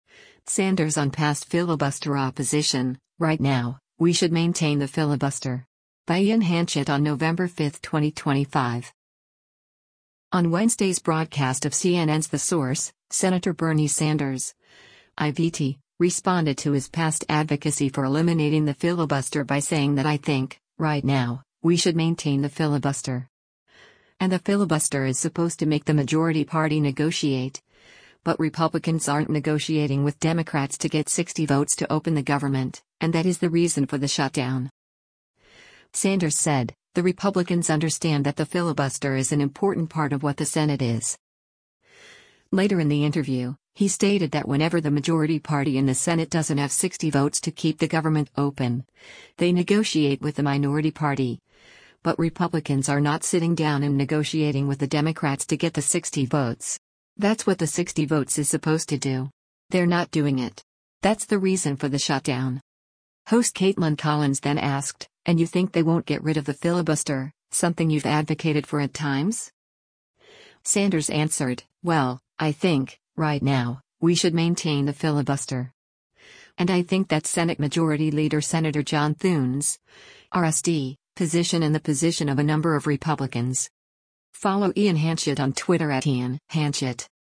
On Wednesday’s broadcast of CNN’s “The Source,” Sen. Bernie Sanders (I-VT) responded to his past advocacy for eliminating the filibuster by saying that “I think, right now, we should maintain the filibuster.”
Host Kaitlan Collins then asked, “And you think they won’t get rid of the filibuster, something you’ve advocated for at times?”